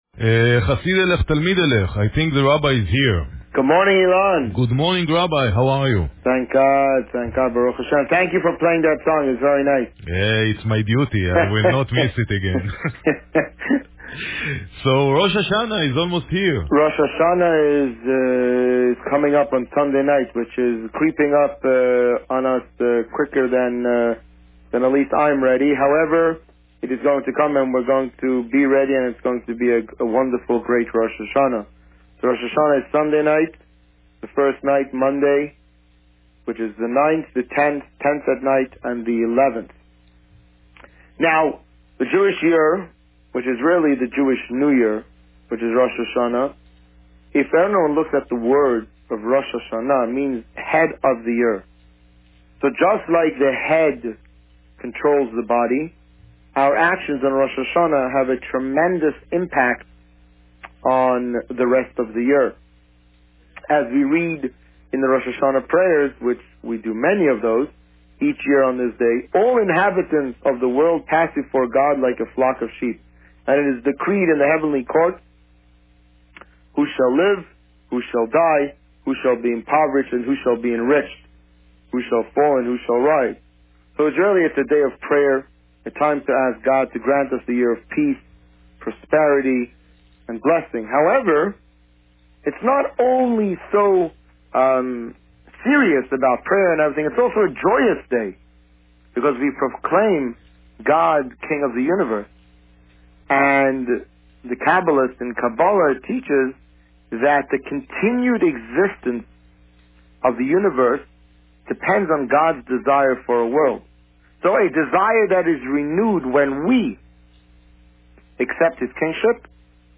Today, the rabbi had the second of two interviews regarding preparation for the upcoming Rosh Hashana holiday.